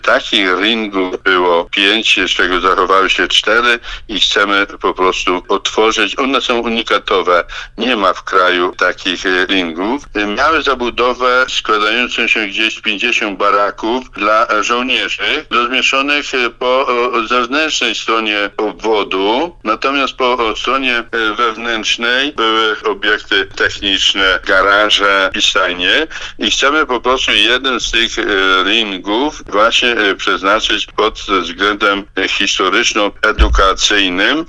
Chcemy jeden z tych 'Ringów’ przeznaczyć na cel historyczno-edukacyjny – mówił wójt gminy Dębica Stanisław Rokosz, który był gościem rozmowy Słowo za Słowo.